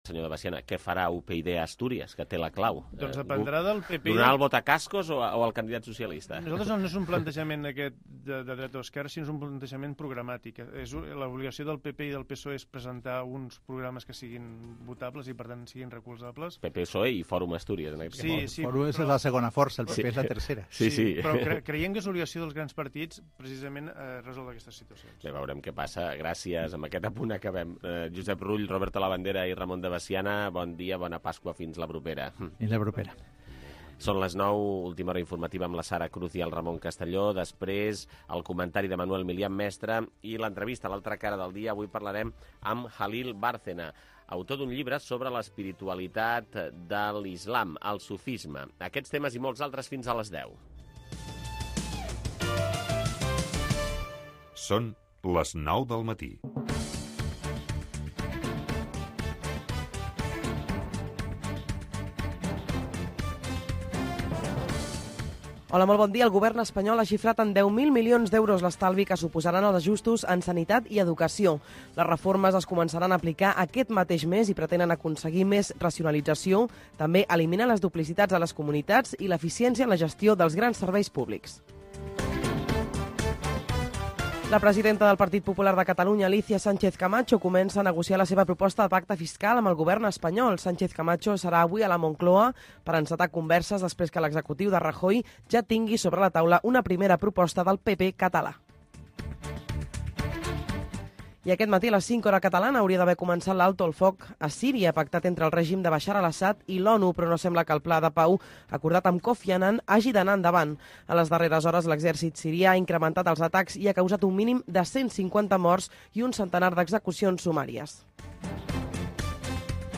El primer cafè. Informació, actualitat, espais, 2 hores d'un magazin matinal